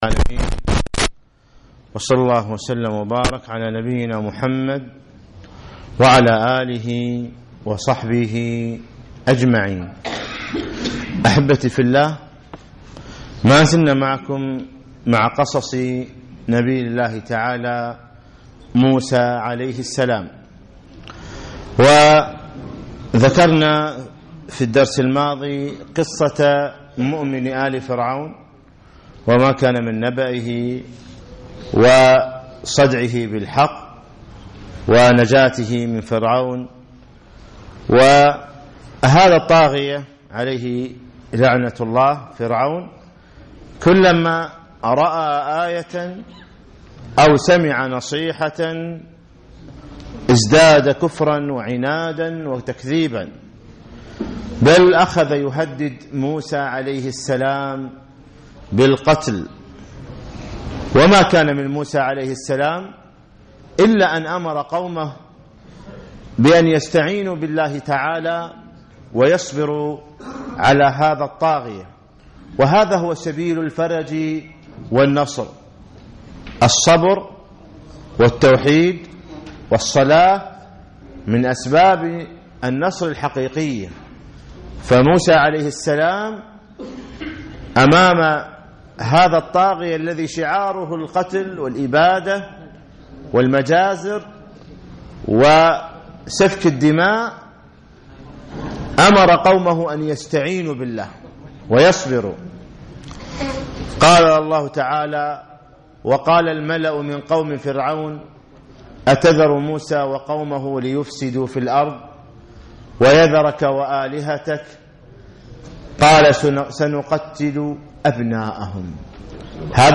الجمعة 26 ربيع الثاني 1437 الموافق 5 2 2016 مسجد عطارد بن حاجب الفروانية